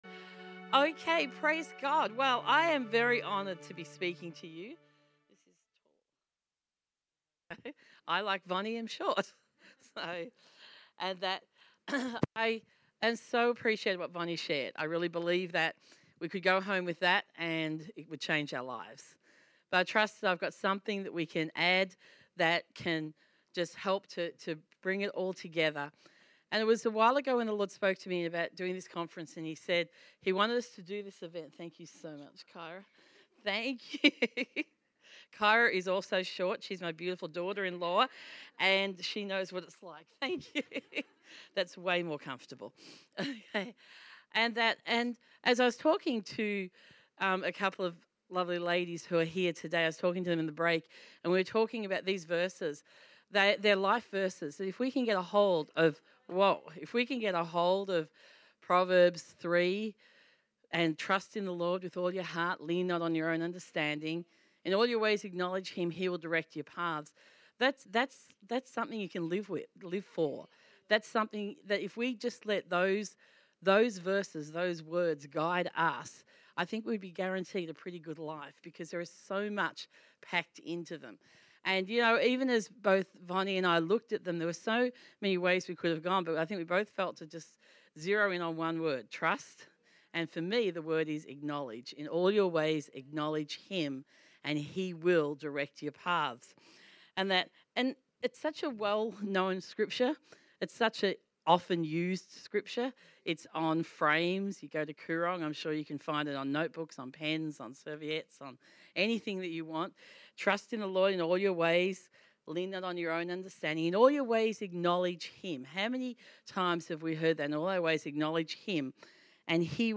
LADIES CONFERENCE - Session 2 Speaker